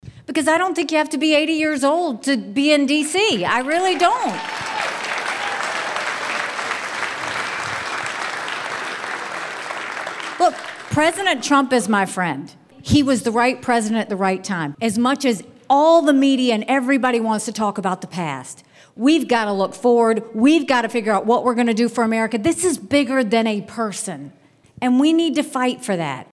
Haley spoke to over 300 people last (Monday) night in Urbandale. A man in the crowd noted Donald Trump won Iowa in the 2016 and 2020 General Elections and asked Haley why Iowa Caucus goers should vote for her rather than someone like Trump.